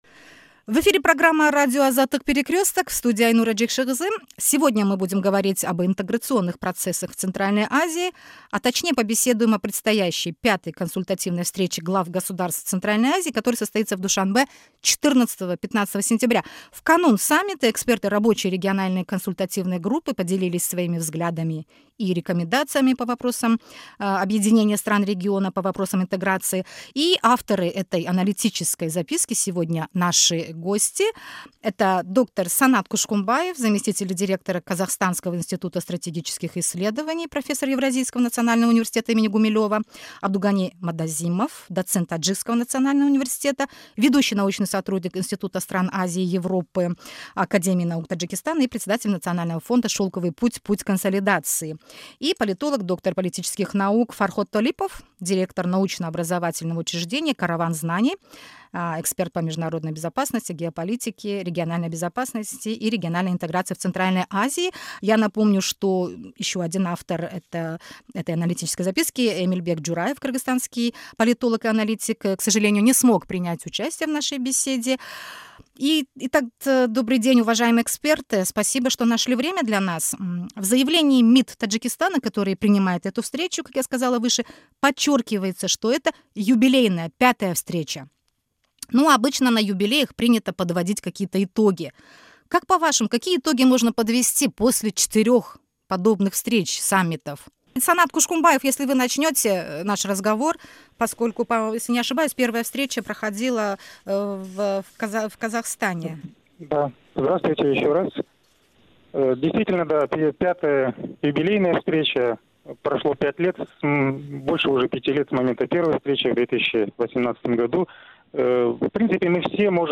В канун V Консультативной встречи глав государств Центральной Азии, которая состоится в Душанбе 14-15 сентября, эксперты поделились своими взглядами и рекомендациями по вопросам интеграции стран региона. Авторы этой аналитической записки выступили в эфире «Азаттыка».